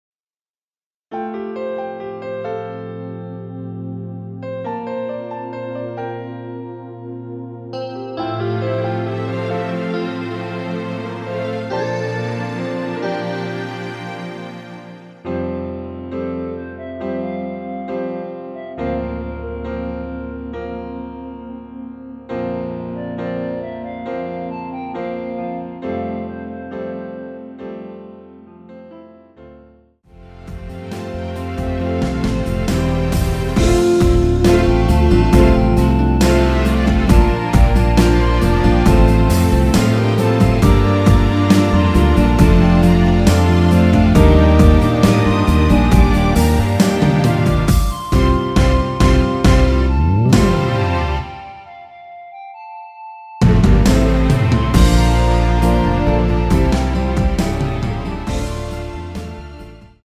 원키에서(-1)내린 멜로디 포함된 MR입니다.(미리듣기 참조)
앞부분30초, 뒷부분30초씩 편집해서 올려 드리고 있습니다.
중간에 음이 끈어지고 다시 나오는 이유는